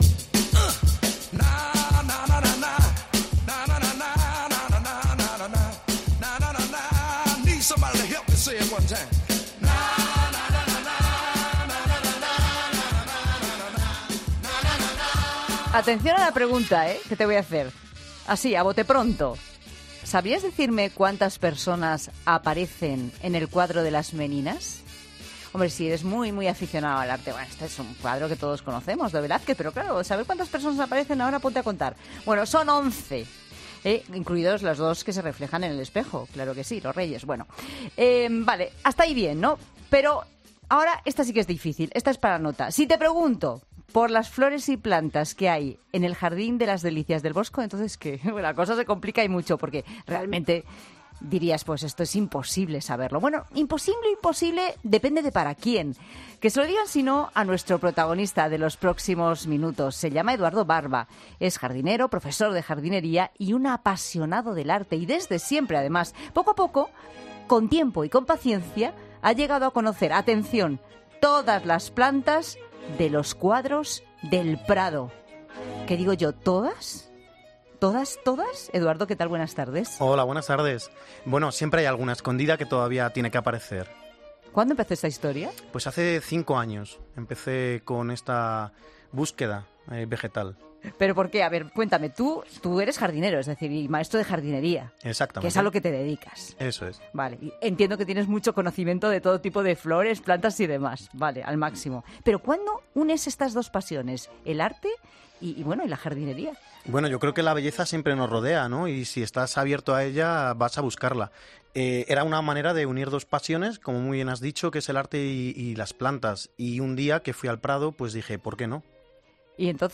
Si te apasiona el arte y la naturaleza debes escuchar la siguiente entrevista.